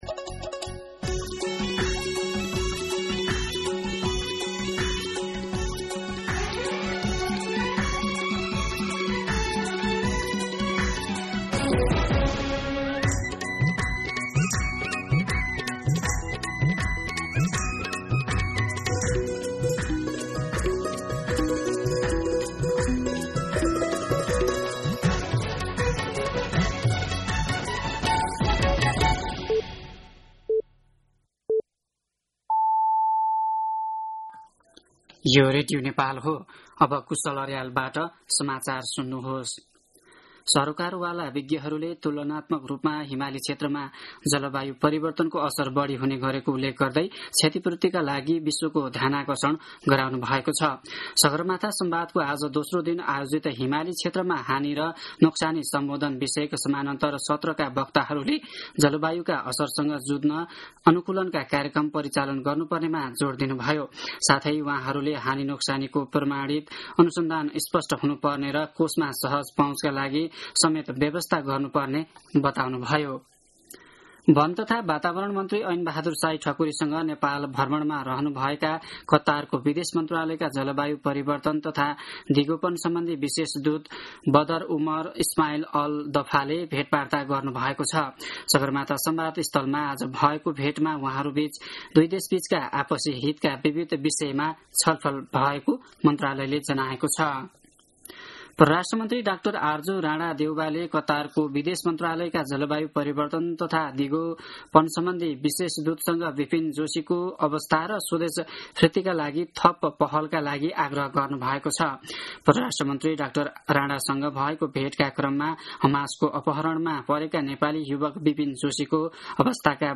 An online outlet of Nepal's national radio broadcaster
दिउँसो ४ बजेको नेपाली समाचार : ३ जेठ , २०८२
4-pm-Nepali-News-3.mp3